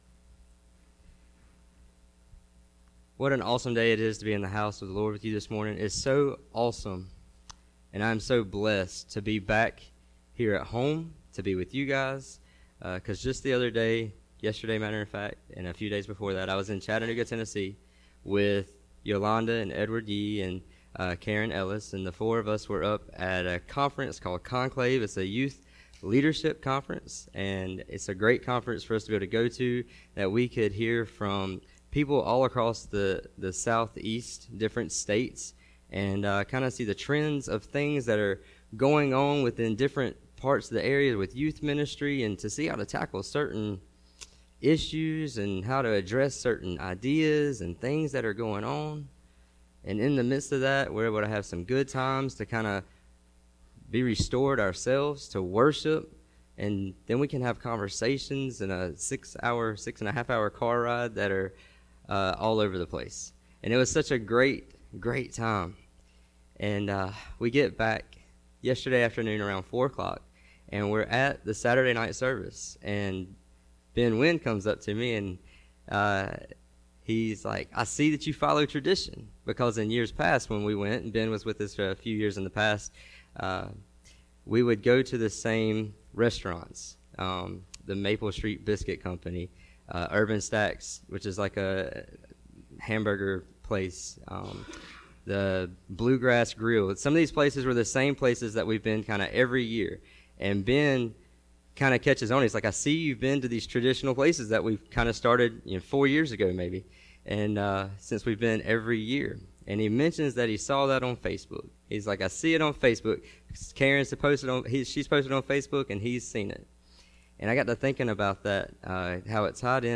Sermon-1-27.mp3